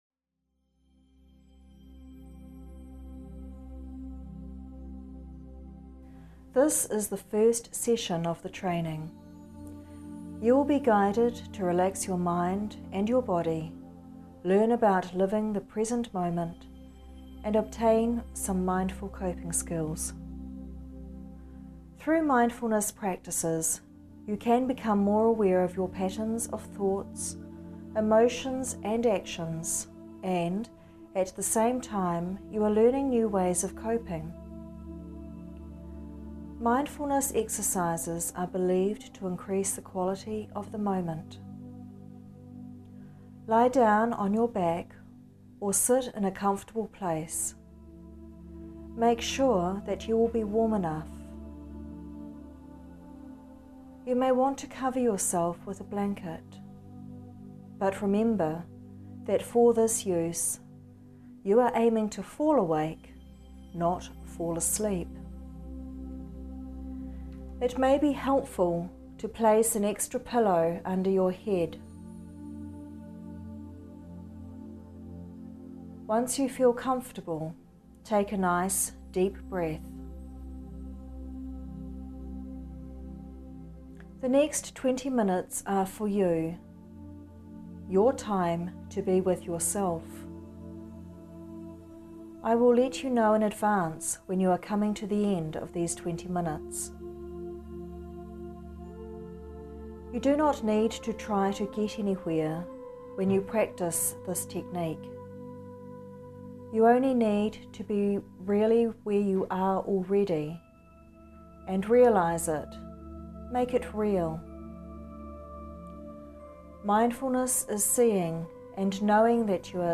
Session one contains guided meditation (breathing and relaxation techniques, and the body scan) to increase awareness and focus on the present moment and on mindful coping skills.